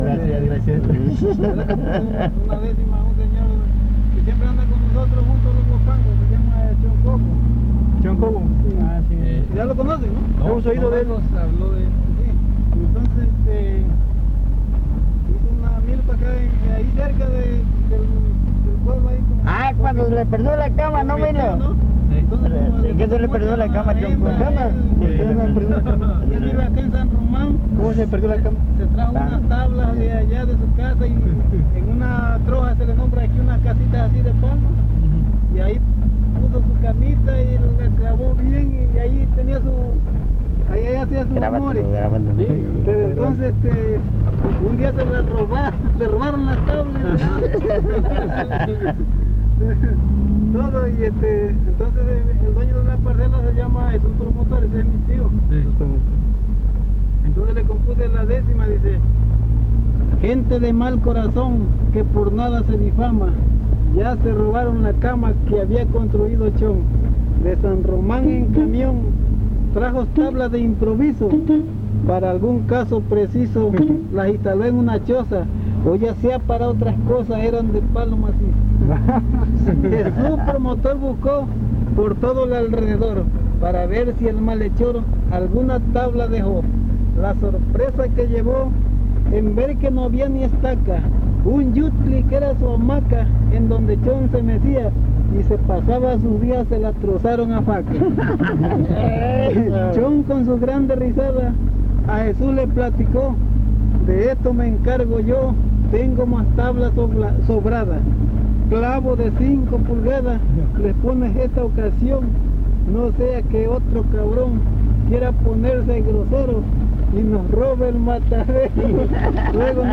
Fiesta de Santiago Tuxtla : investigación previa